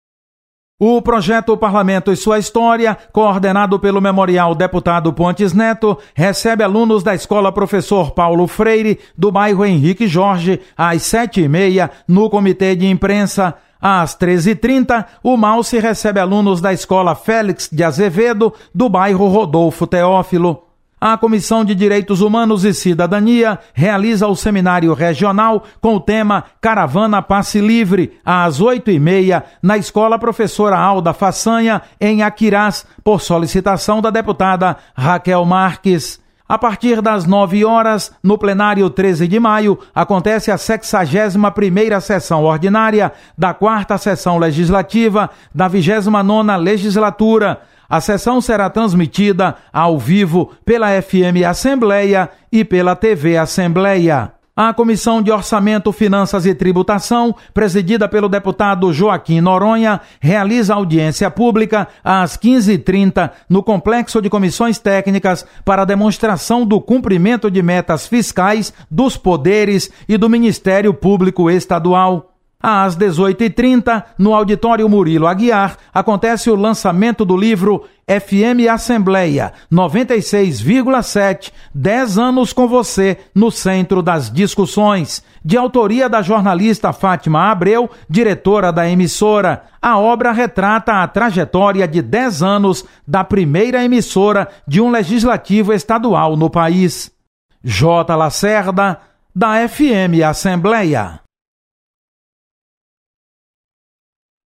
Acompanhe as atividades desta quarta-feira da Assembleia Legislativa com o repórter